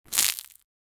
Cuisine Sound Effects - Free AI Generator & Downloads
a-sfx-of-fresh-lettuce-snafel2j.wav